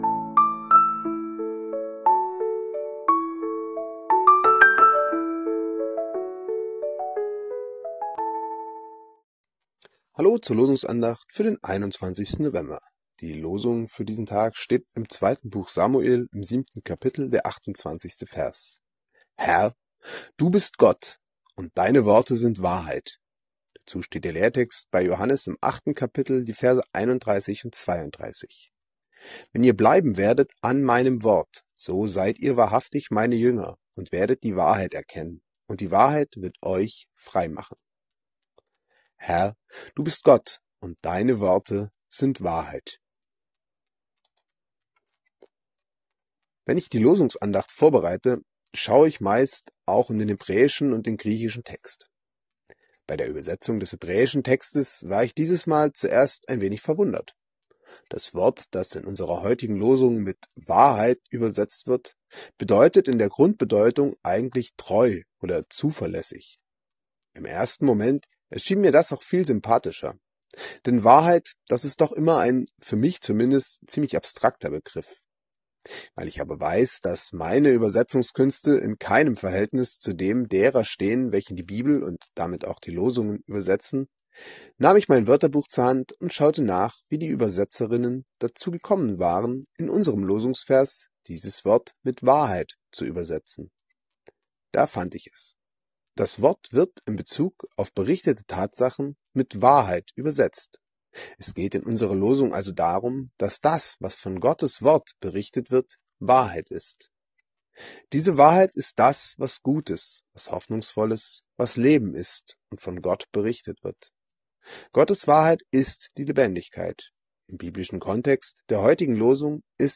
Losungsandacht für Freitag, 21.11.2025